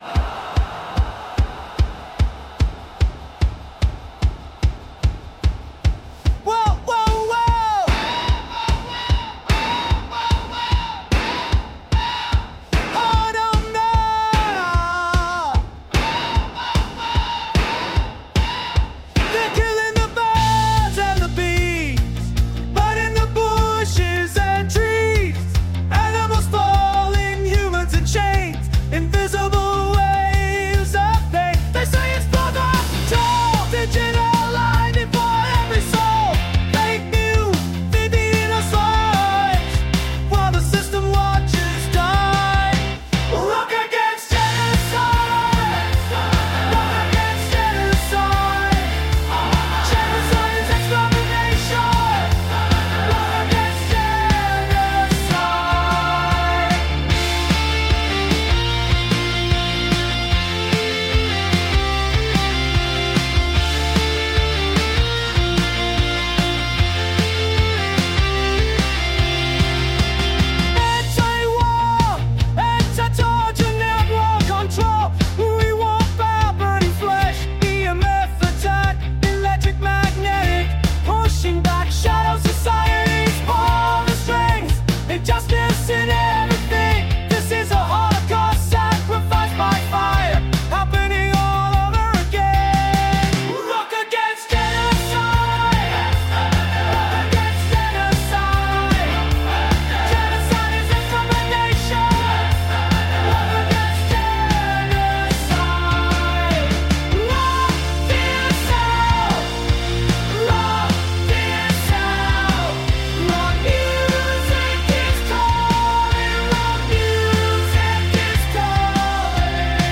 U TUBE ROCK AGAINST GENOCIDE R.A.G!!!